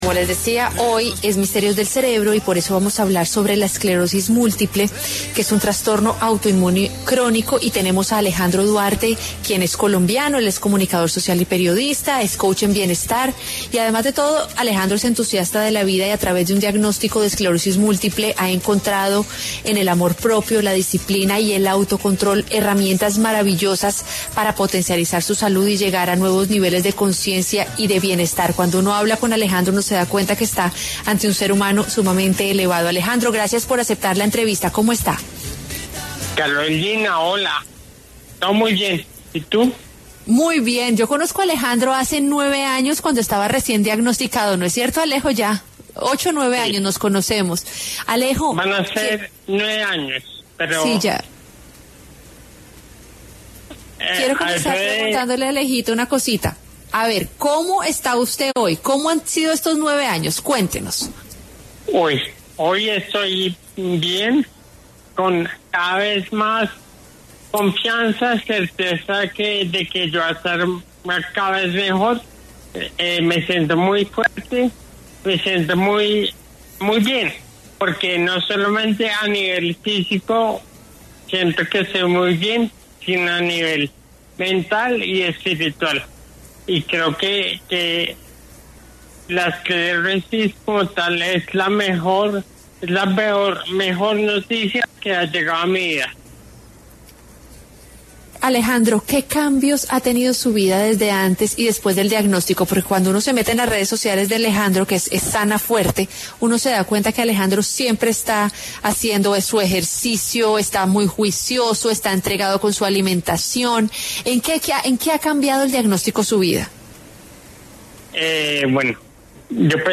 ENTREVISTA: ABC DE LA ESCLEROSIS MÚLTIPLE EXPLICADA POR EXPERTO: ¿ES HEREDITARIA? ¿TIENE CURA?
Entrevista-Esclerosis-Multiple.mp3